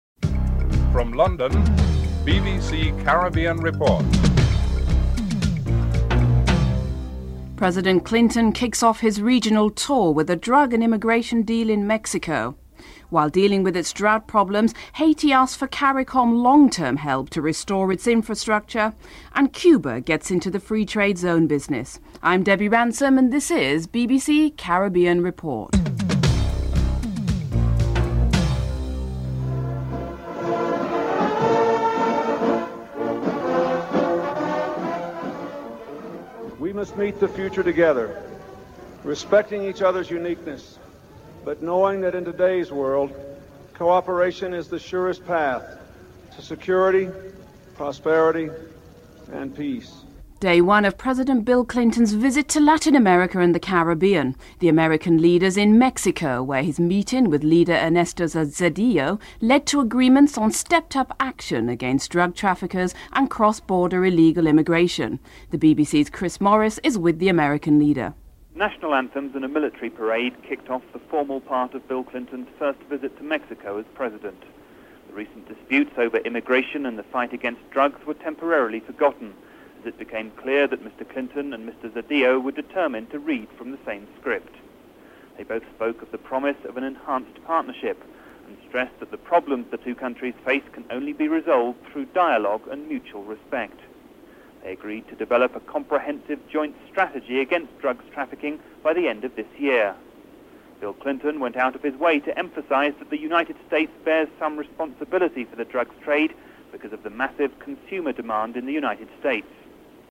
President Bill Clinton is interviewed.